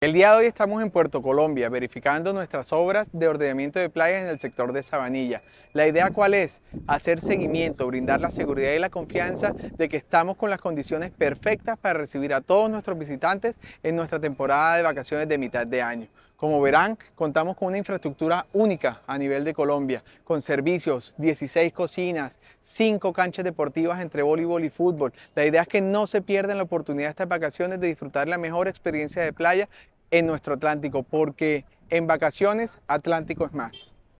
Jaime Alfaro, subsecretario de Turismo
Audio-Jaime-Alfaro-subsecretario-de-Turismo-recorrido-playa-Sabanilla-sector-Country.mp3